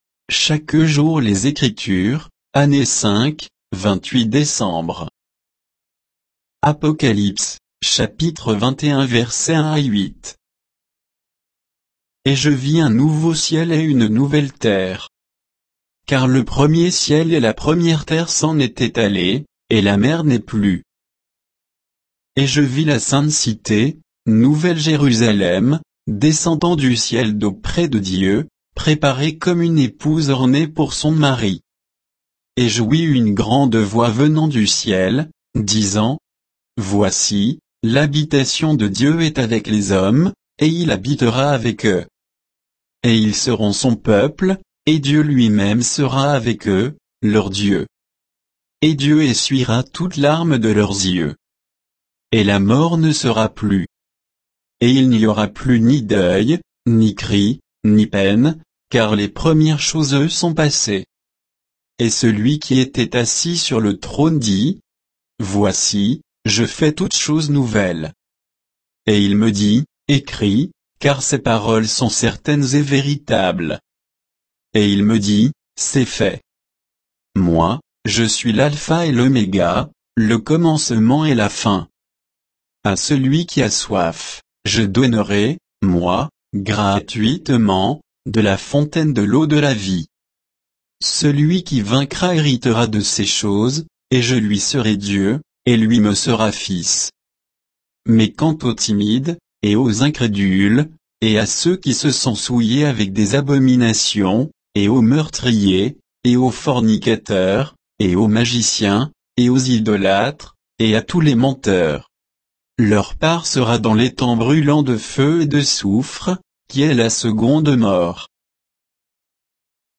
Méditation quoditienne de Chaque jour les Écritures sur Apocalypse 21